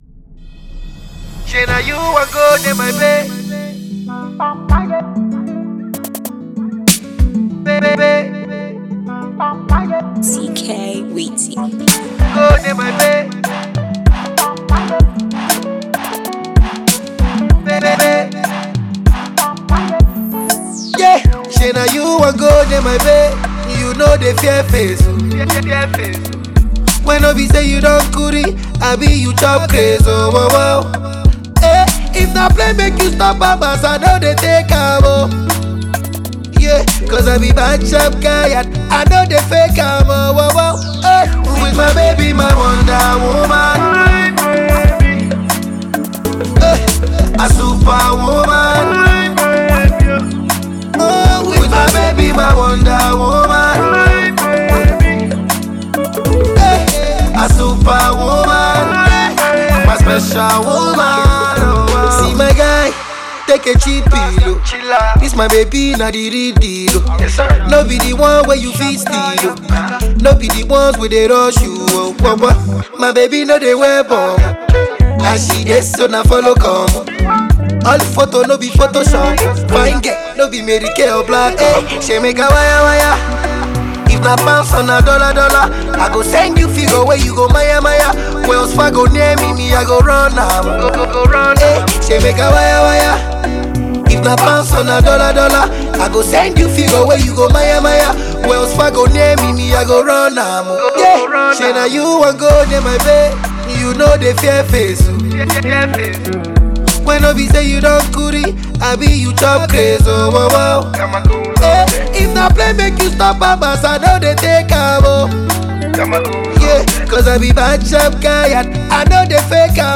mid-tempo single